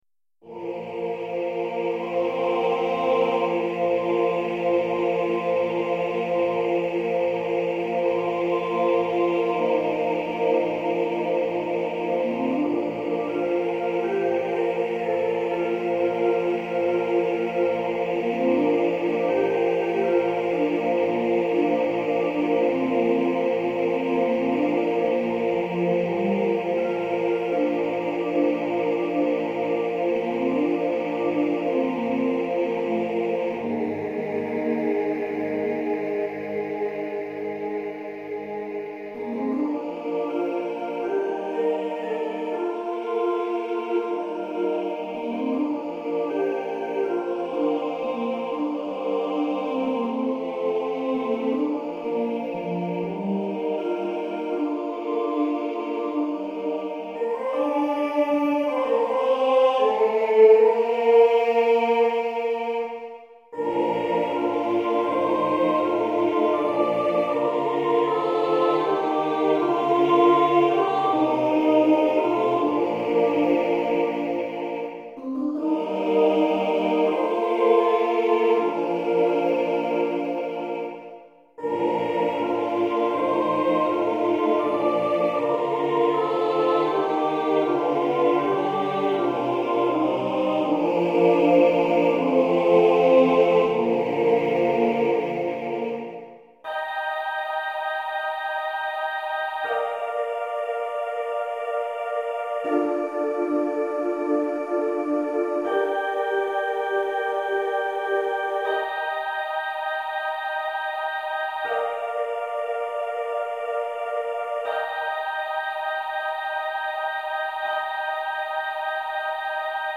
Köpenhamn 4/9 2011
Gabriellas sång dator